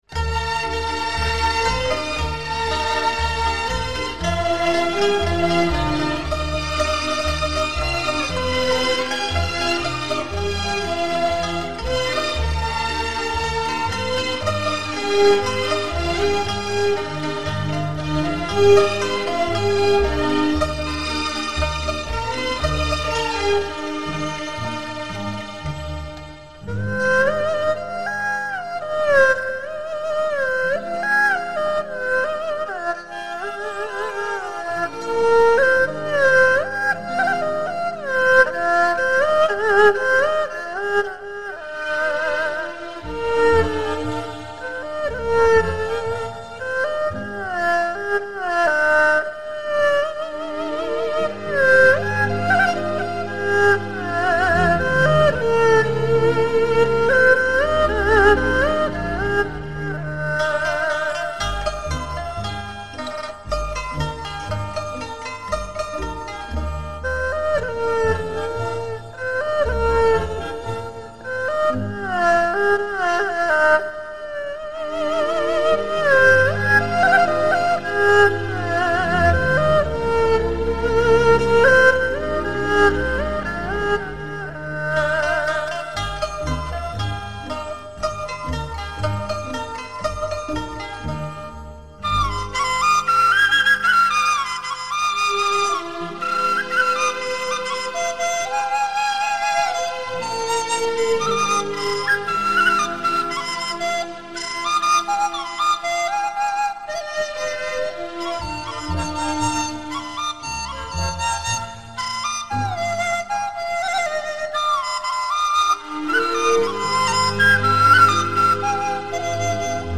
【所属类别】音乐 国乐/民族
陕西民歌外在苍劲、悲凉、雄浑、质朴。
本辑是陕西民歌改编的轻音乐。以中国民族乐器为主奏、领奏，辅以西洋管弦乐器及电声乐器。
最新数码系统录制，值得您永远珍藏。